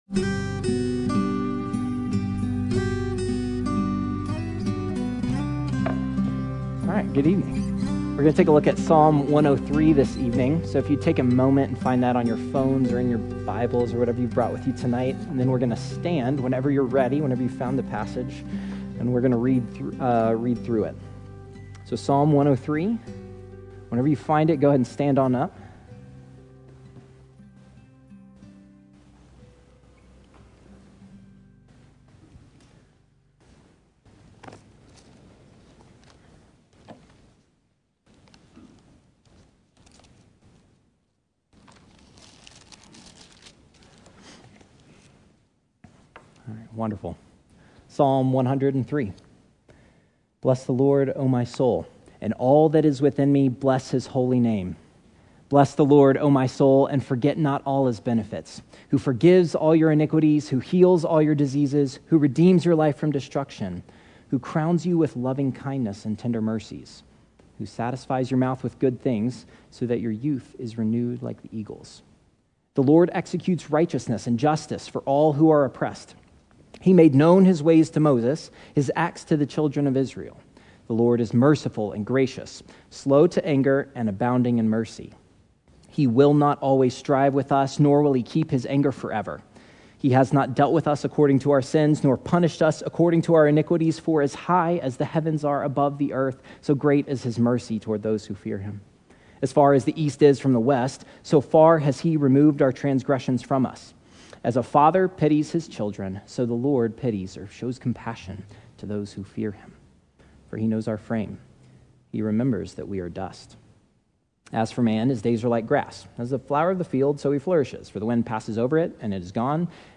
Sunday Bible Study « To Love & To Cherish